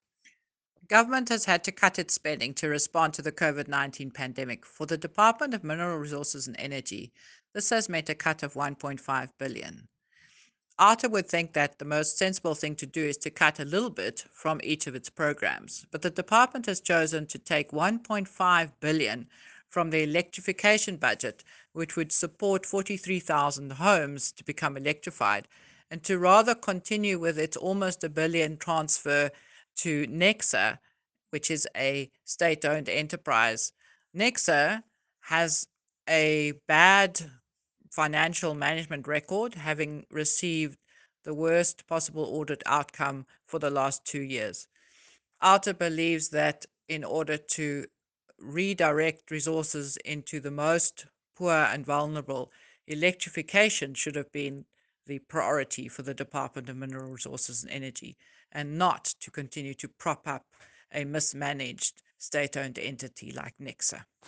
Voice note: